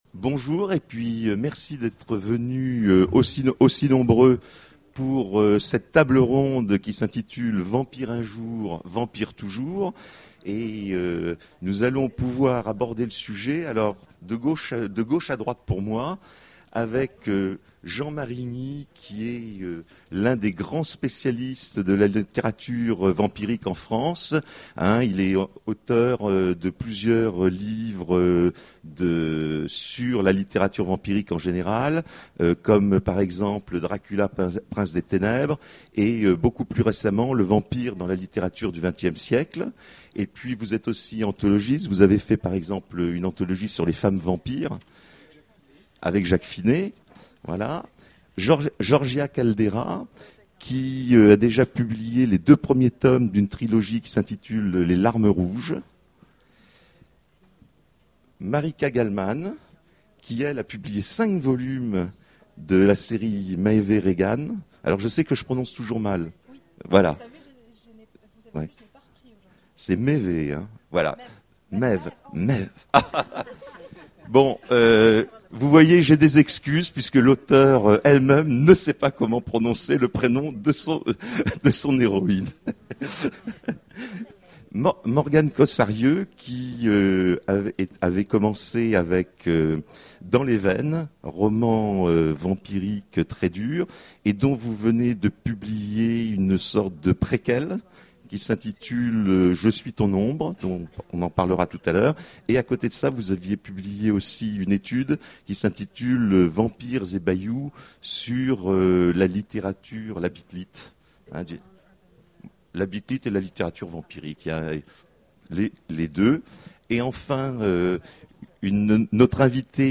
Imaginales 2014 : Conférence Vampire un jour, vampire toujours!